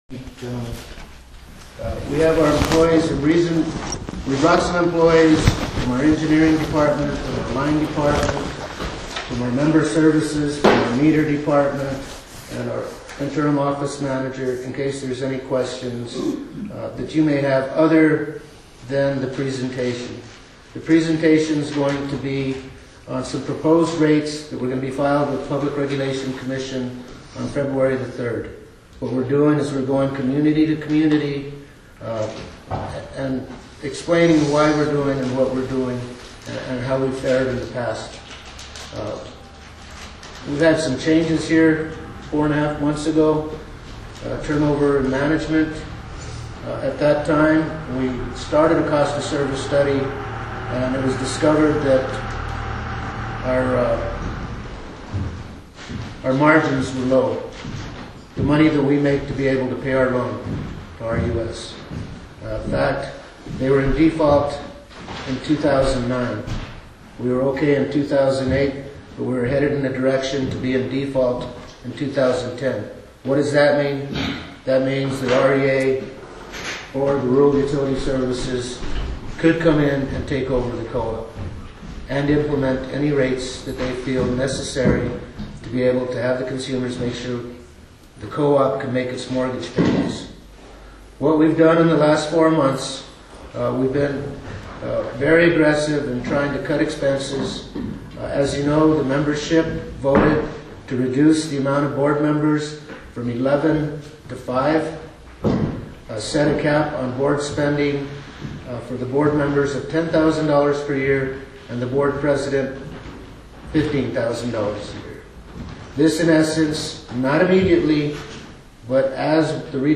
Quemado Rate Presentation (Audio)